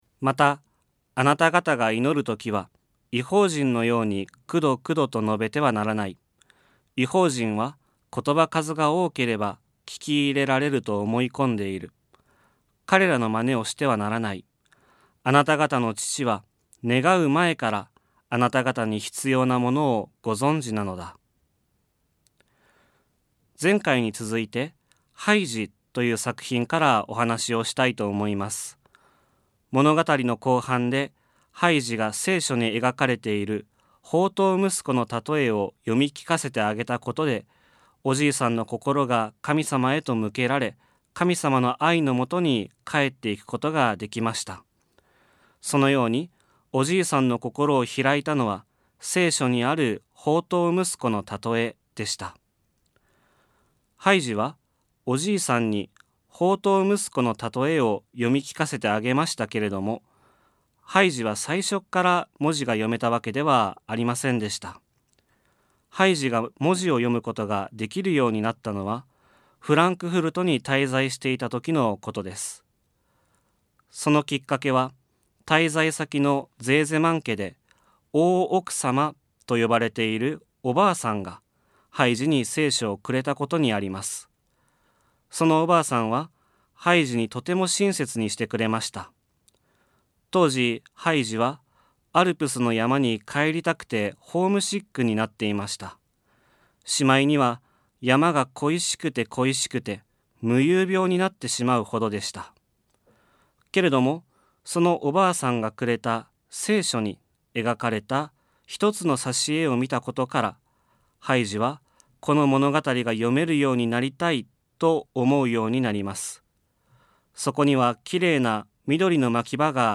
」 聖書はマタイによる福音書より ラジオ番組「キリストへの時間」